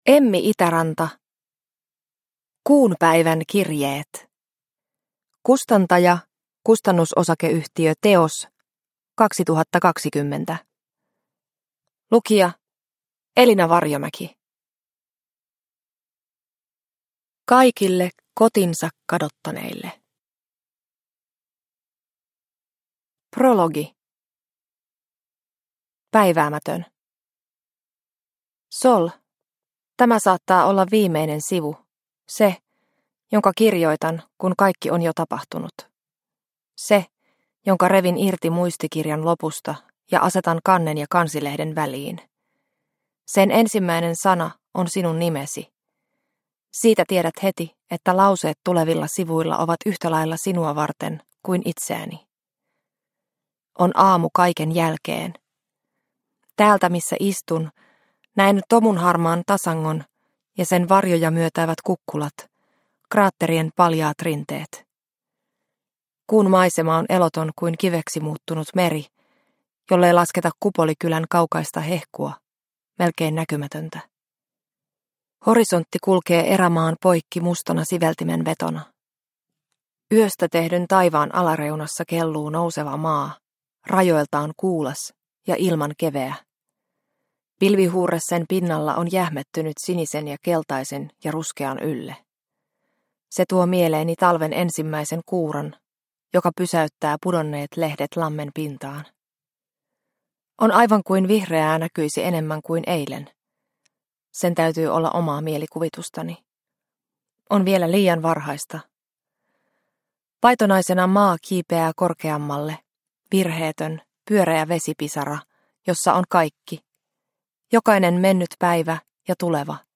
Kuunpäivän kirjeet – Ljudbok – Laddas ner